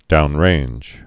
(dounrānj)